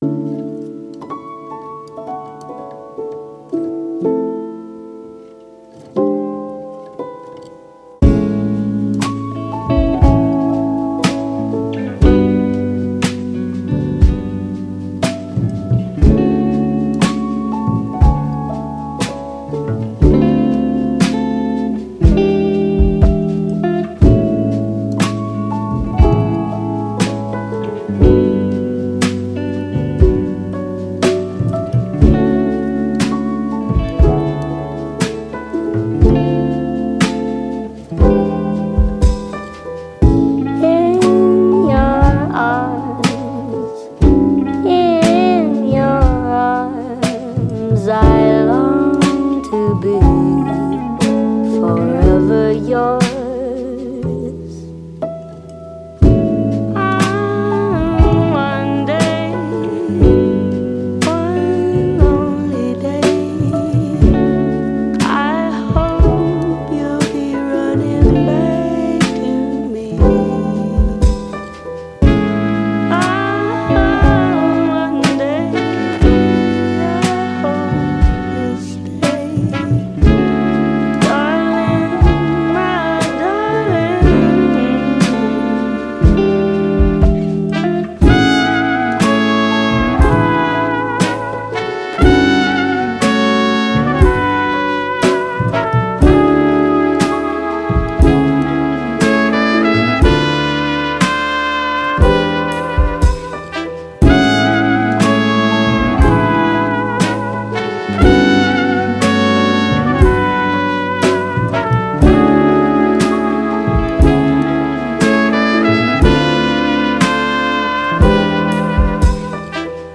Jazz, Vocal, Slow, Thoughtful, Chilled